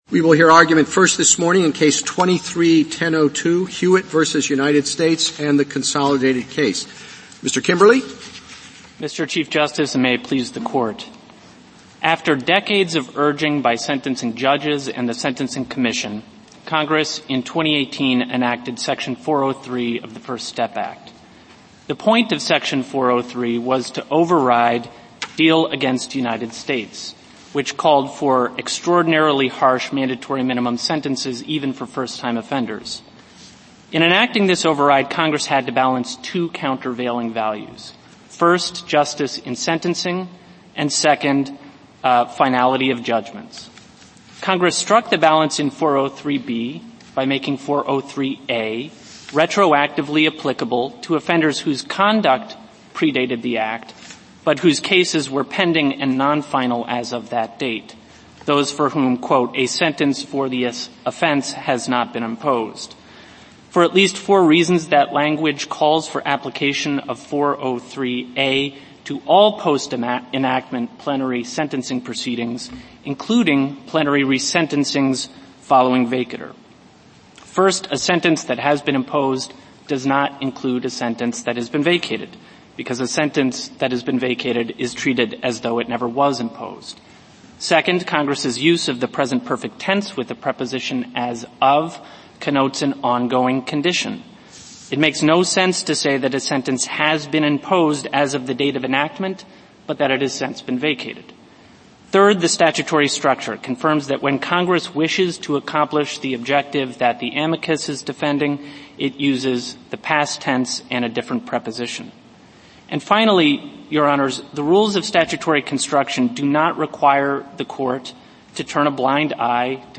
National, Government & Organizations